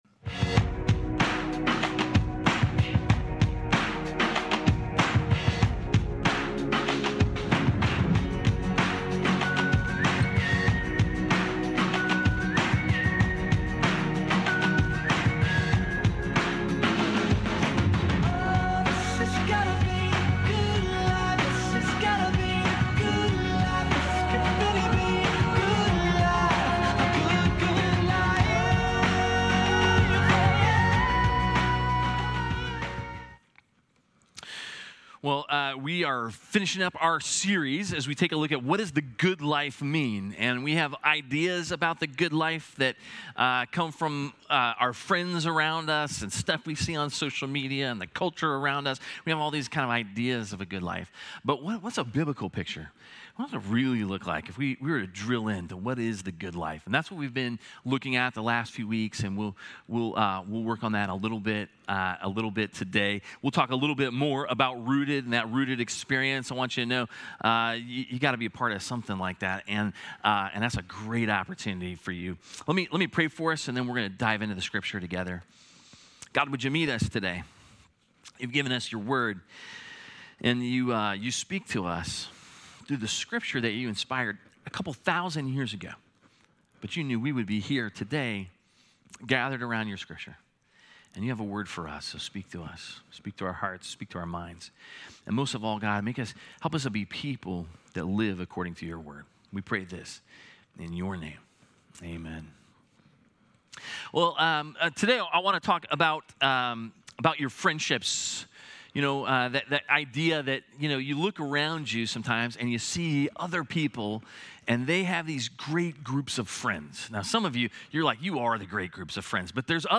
A message from the series "The Good Life." The Sabbath is set aside for us to find true rest in God.